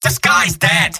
Robot-filtered lines from MvM. This is an audio clip from the game Team Fortress 2 .
{{AudioTF2}} Category:Scout Robot audio responses You cannot overwrite this file.